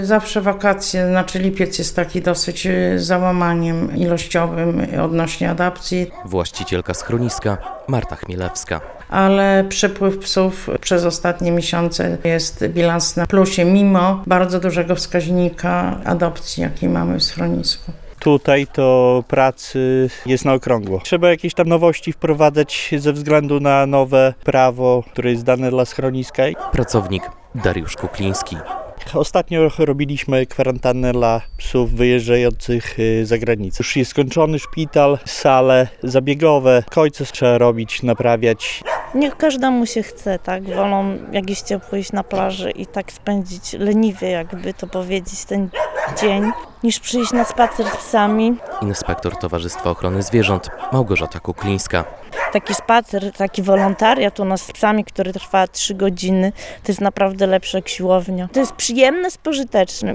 Sonieczkowo apeluje o pomoc - relacja